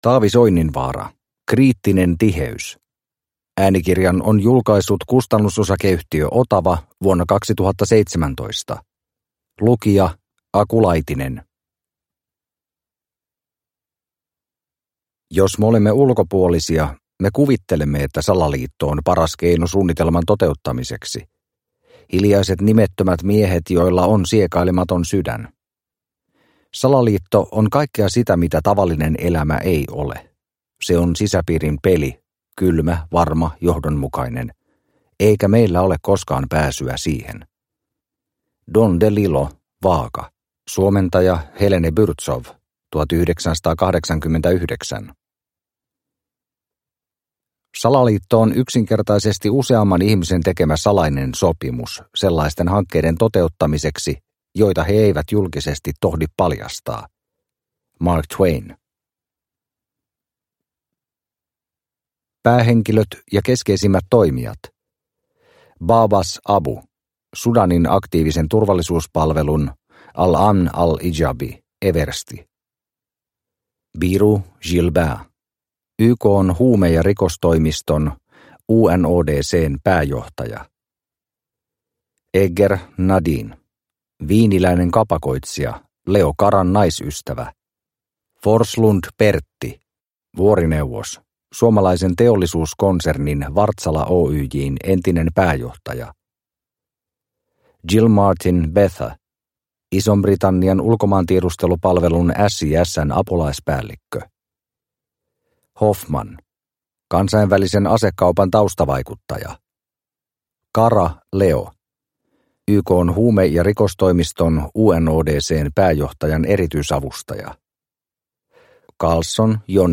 Kriittinen tiheys – Ljudbok – Laddas ner